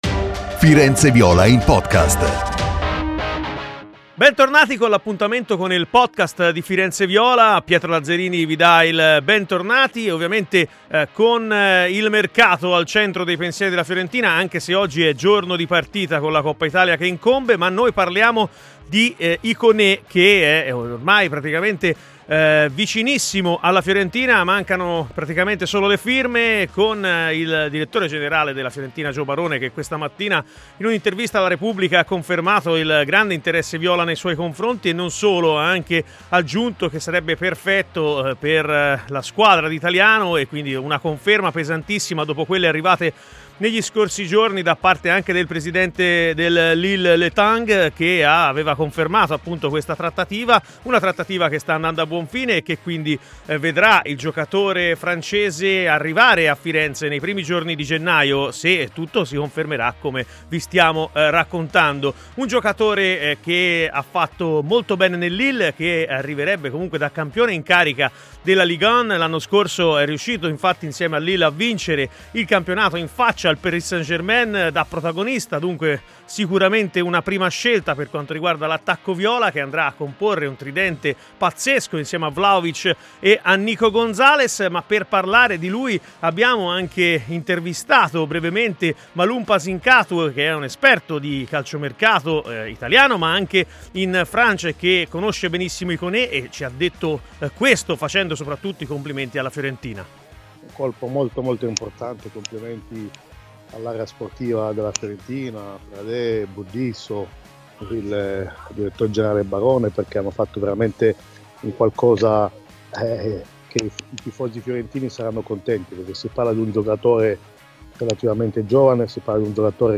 Per ascoltare l'intera intervista e tutte le novità in merito all'arrivo di Ikoné in viola, clicca sul link in basso!